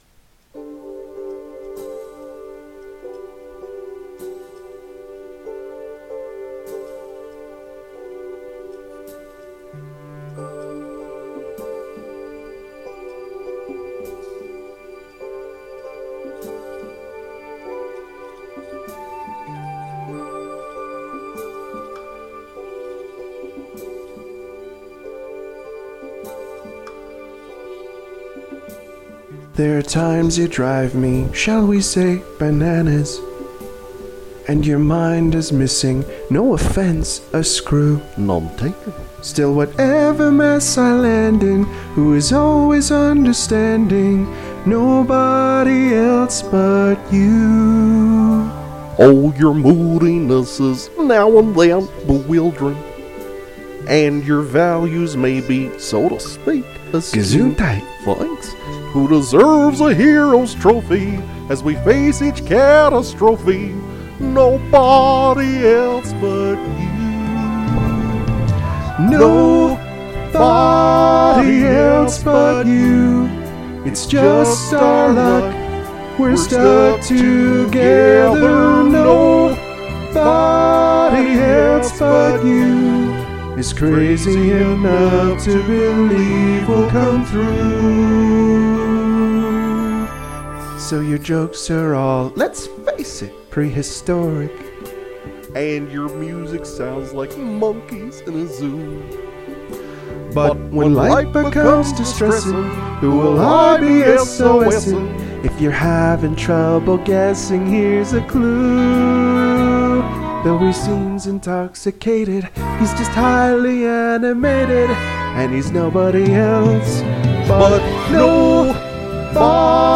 baritone
tenor
vocal cover